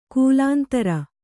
♪ kūlāntara